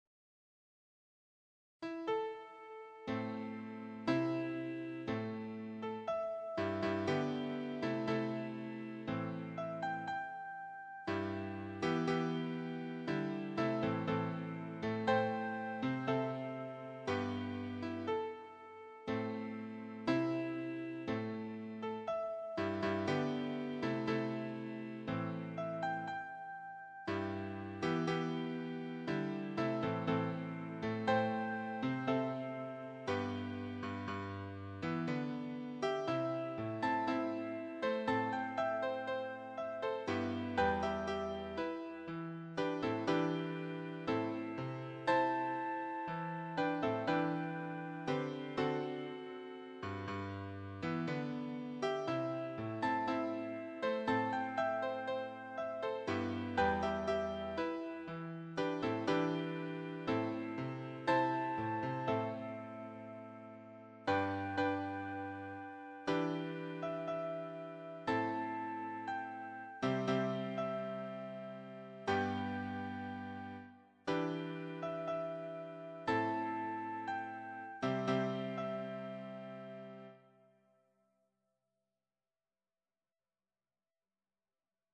choir SAATB